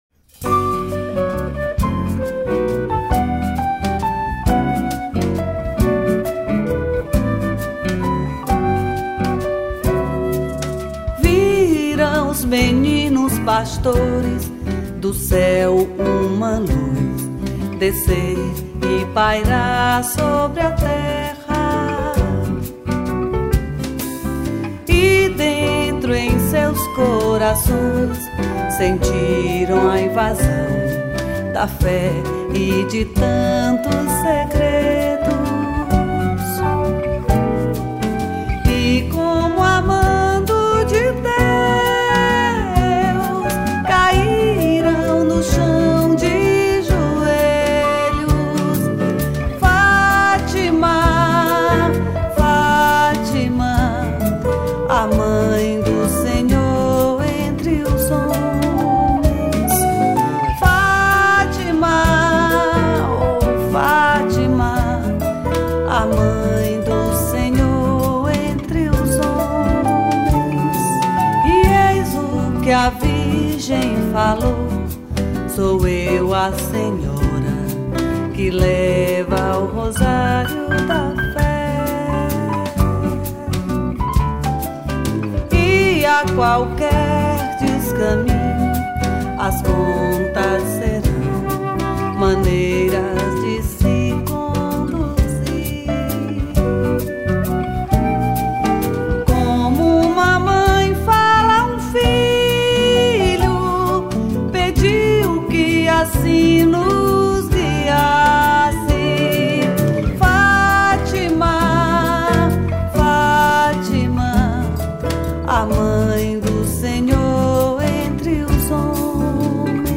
172   03:28:00   Faixa:     Mpb